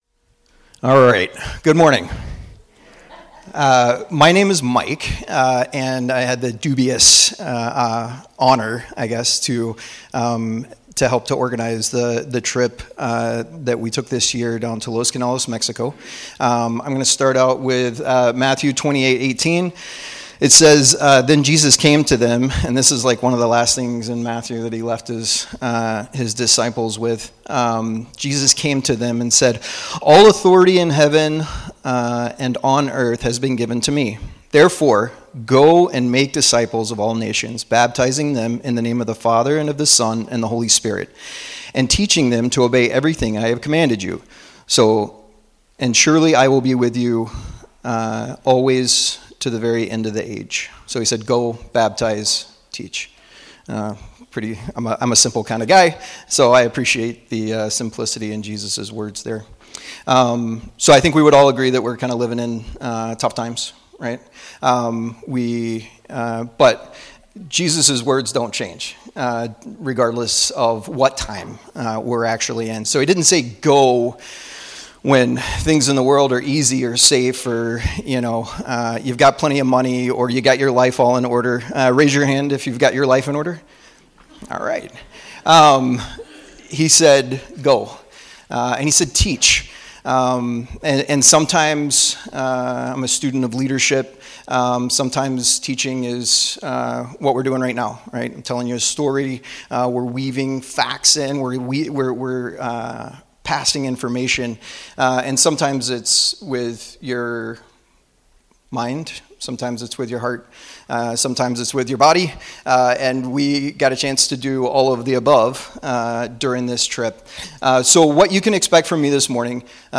Hear stories from our mission team that recently returned from a construction and ministry trip to Los Canelos to serve the ministry of Feed My Lambs International.